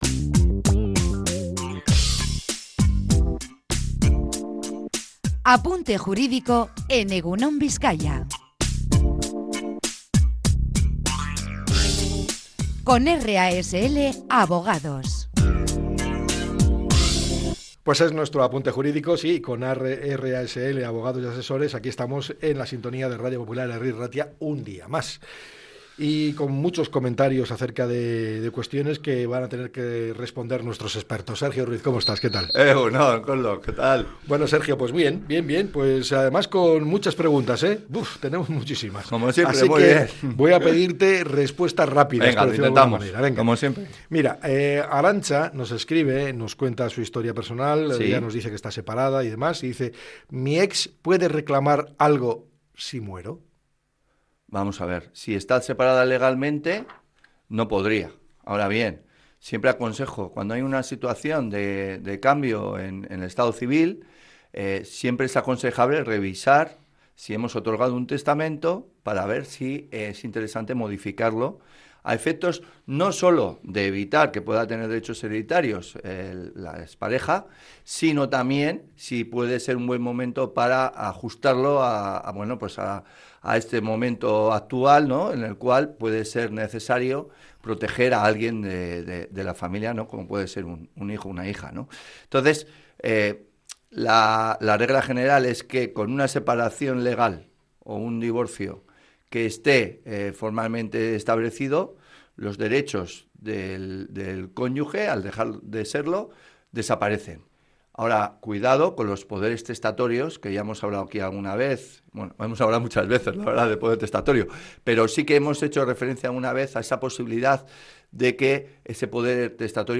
Una oyente preguntó si su ex podría reclamar algo en caso de fallecer.
Otro oyente planteó la posibilidad de que su madre les deje bienes en vida sin perder el control sobre ellos.